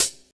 Classic Wonda Hi Hat.wav